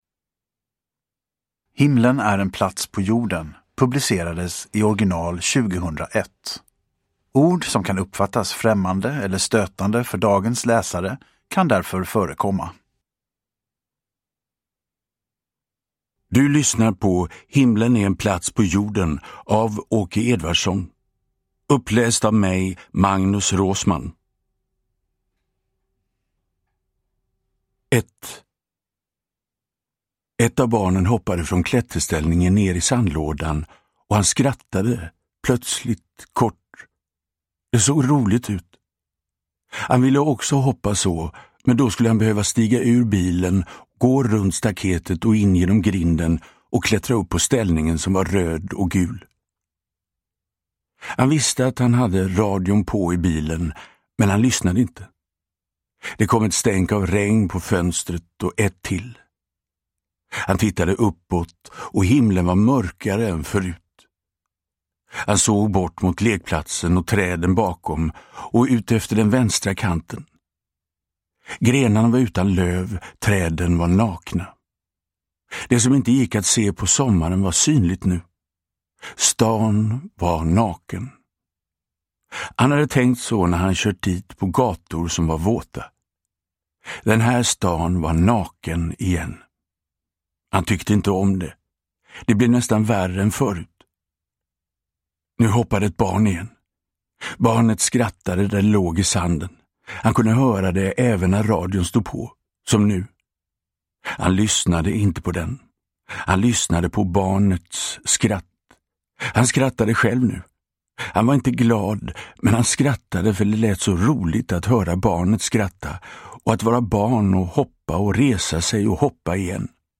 Uppläsare: Magnus Roosmann